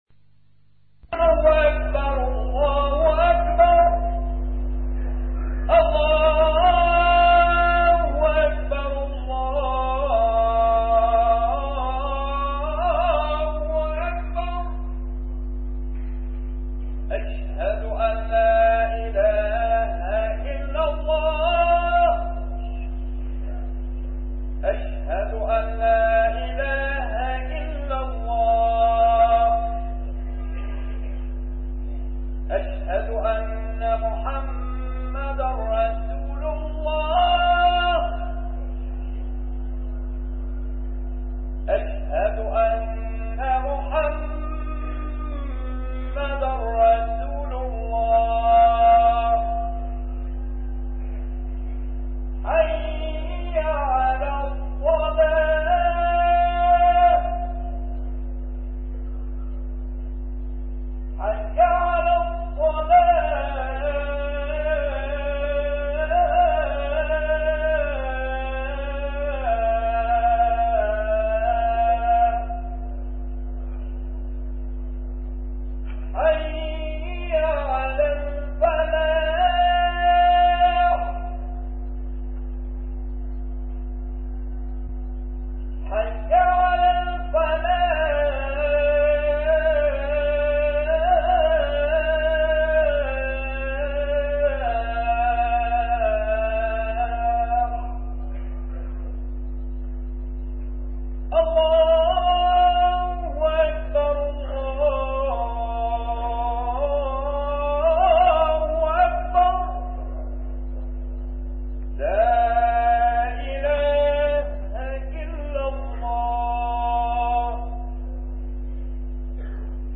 A MARTYR SCHOLAR: IMAM MUHAMMAD SAEED RAMADAN AL-BOUTI - الخطب - من أين يبدأ حل كل المشكلات؟